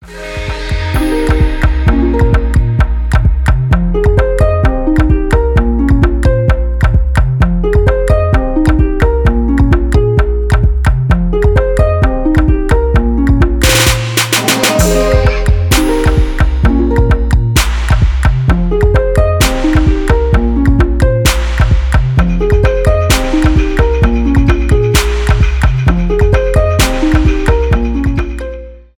без слов , trap